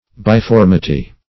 \Bi*form"i*ty\